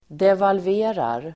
Ladda ner uttalet
Uttal: [devalv'e:rar]